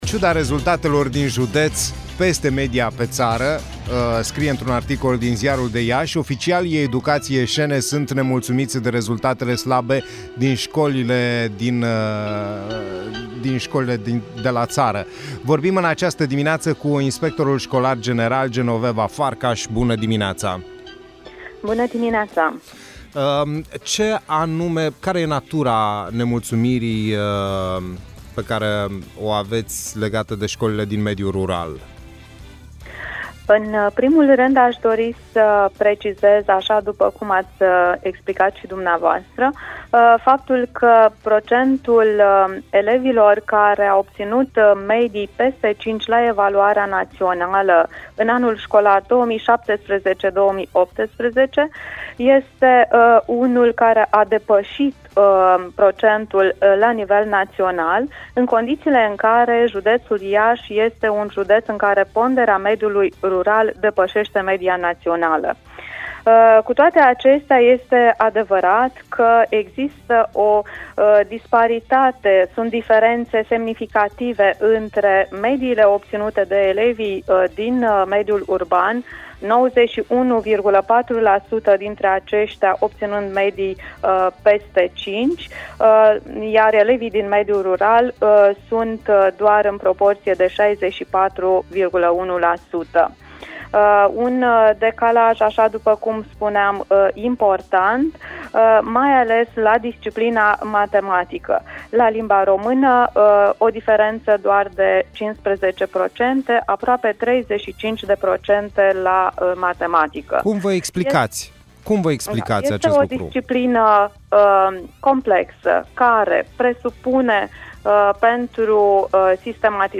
Ascultă integral interviul cu inspectorul general școlar Genoveva Farcaș